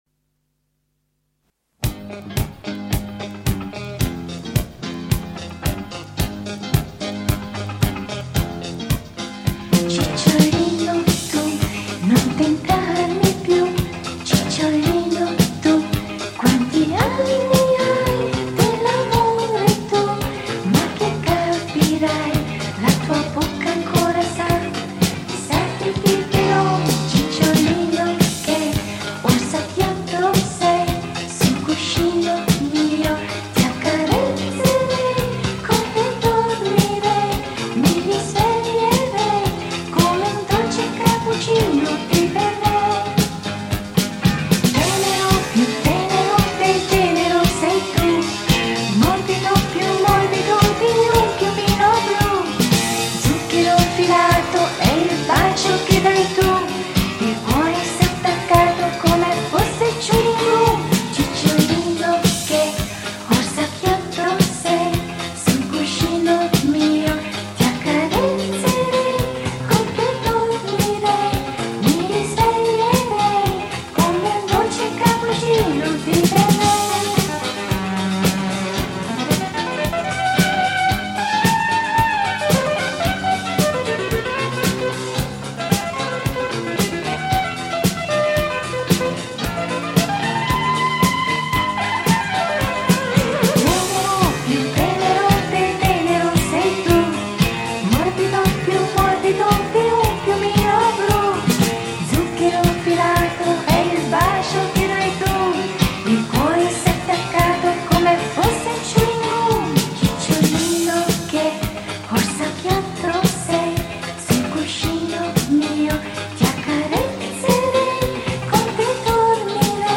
موسیقی دهه ۷۰
سبک دیسکو Disco Music